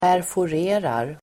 Ladda ner uttalet
Uttal: [pärfor'e:rar]